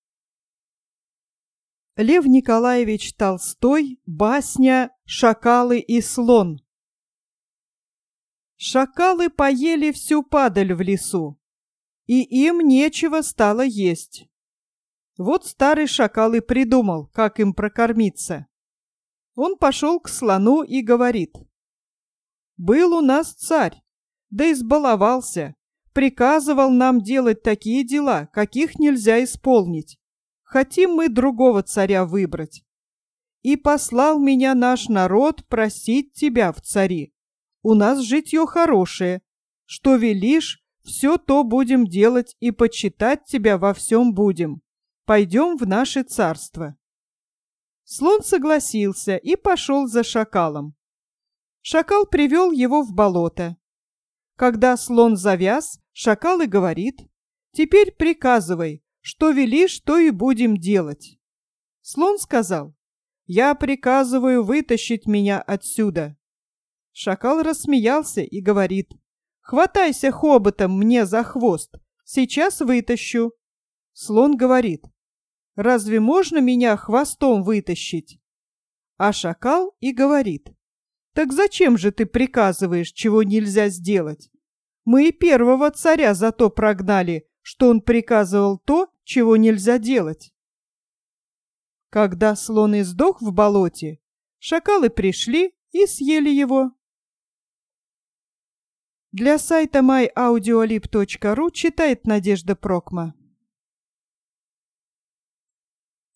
Аудио басня Льва Николаевича Толстого "Шакалы и слон" из "Второй русской книги для чтения" для младших школьников